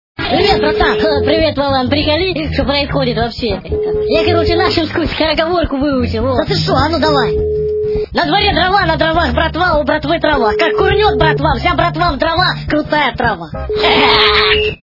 » Звуки » Люди фразы » Скороговорка - Привет братан
При прослушивании Скороговорка - Привет братан качество понижено и присутствуют гудки.